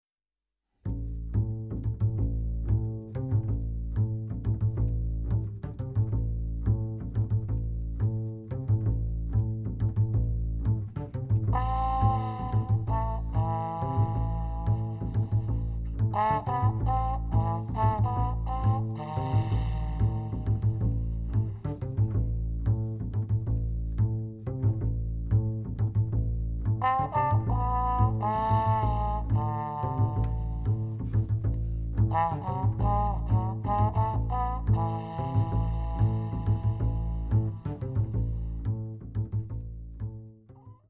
Accordéon / Ténor Lyrique
Trombone
Contrebasse
au Studio Les Tontons Flingueurs (Renaison - France)